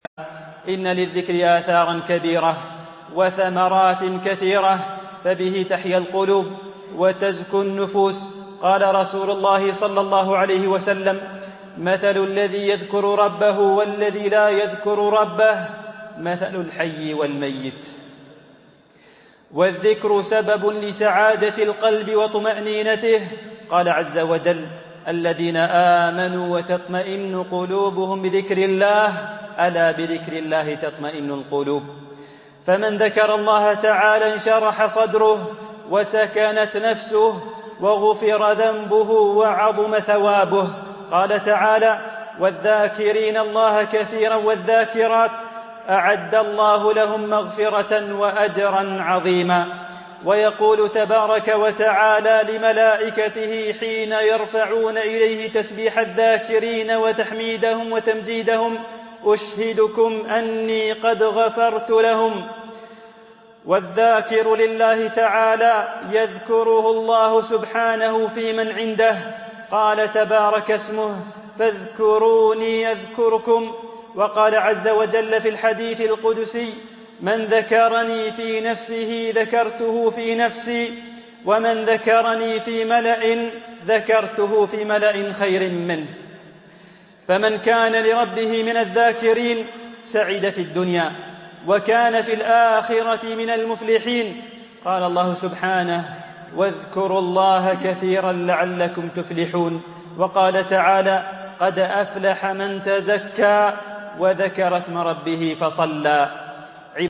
MP3 Mono 22kHz 64Kbps (CBR)